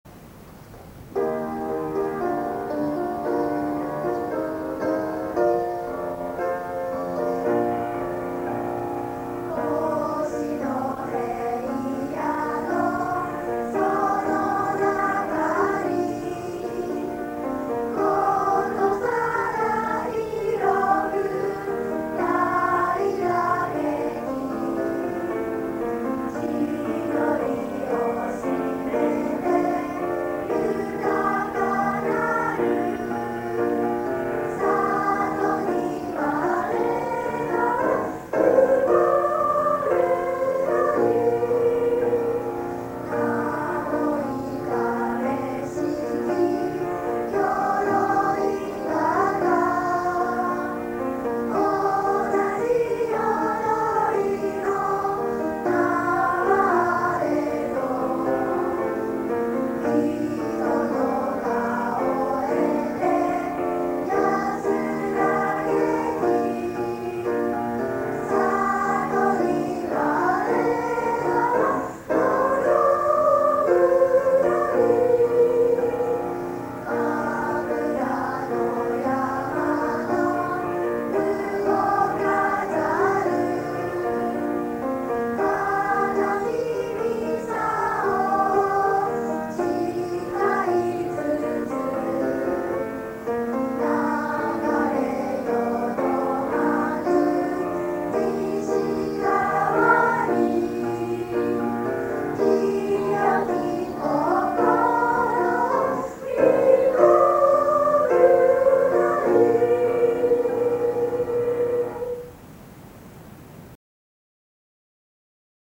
新潟市立鎧郷小学校 | 校歌
校歌の歌詞，主旋律の試聴